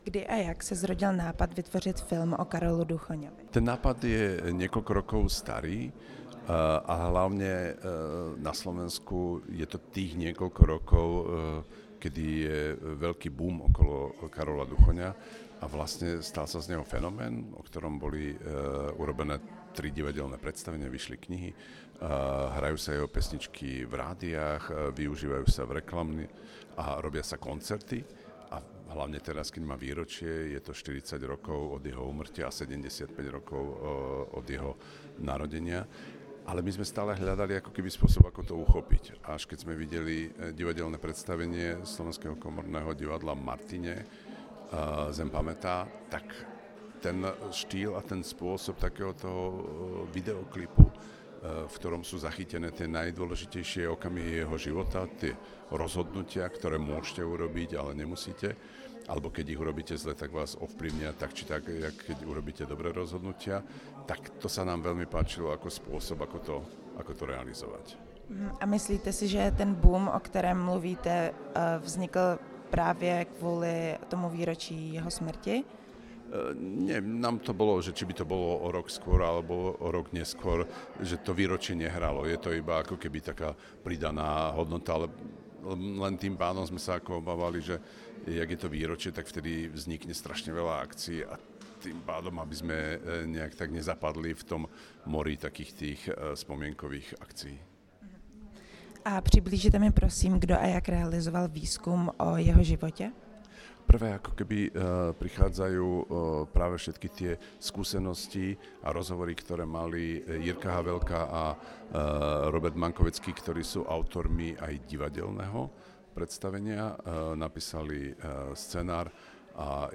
Rozhovor s režisérem